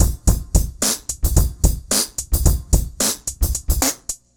RemixedDrums_110BPM_06.wav